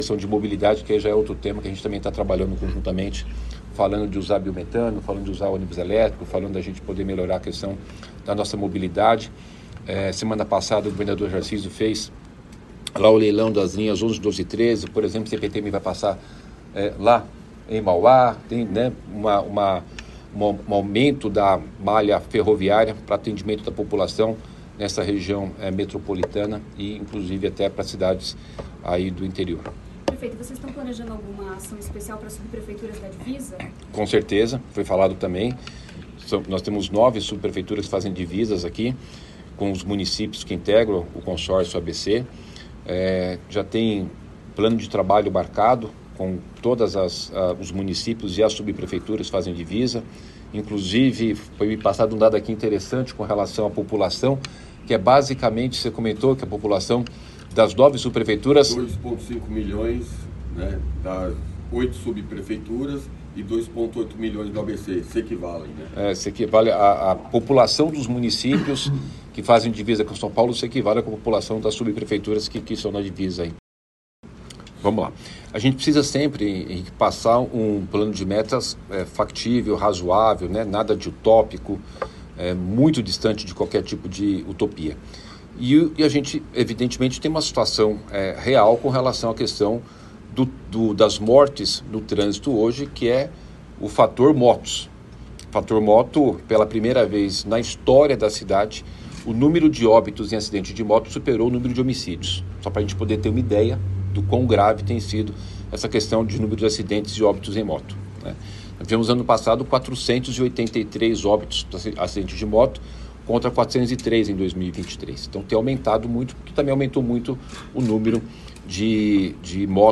O prefeito, em entrevista coletiva com participação do Diário do Transporte, também citou mais uma vez textualmente, só que agora de viva voz, a questão do ônibus a biometano.